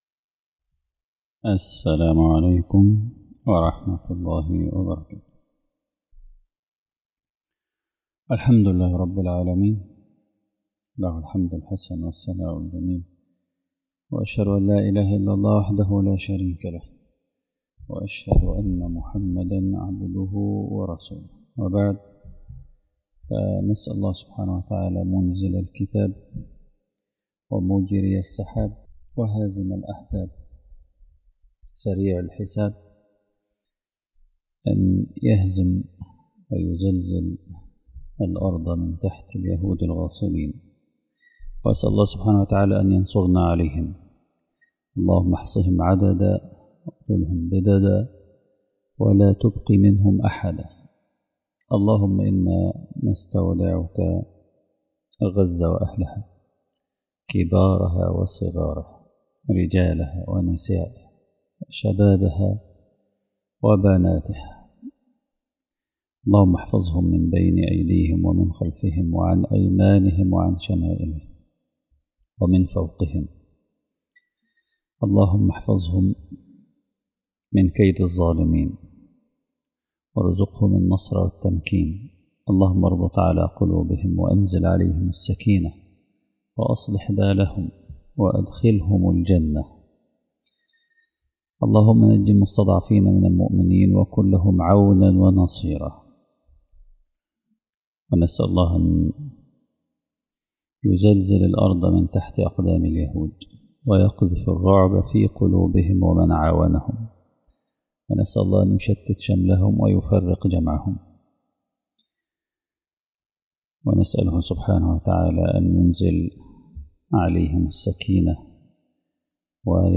المحاضرة الثامنة